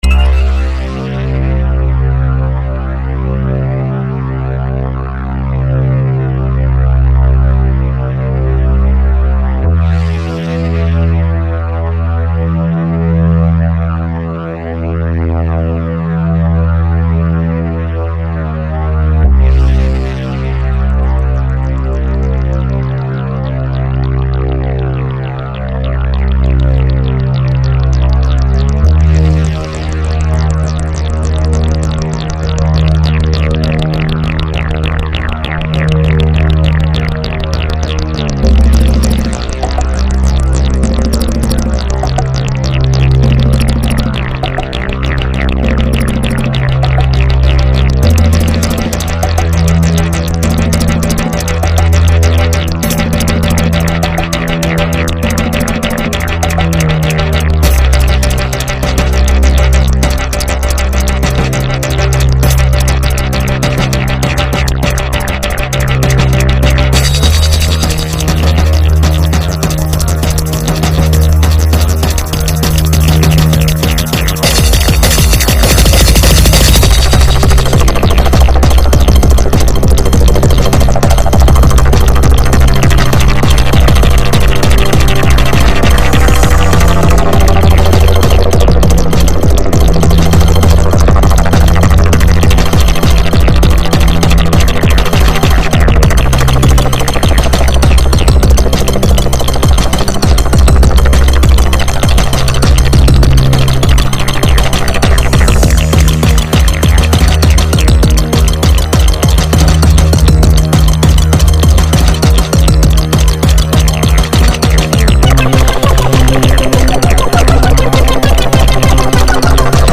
Au casque c'est psychédélique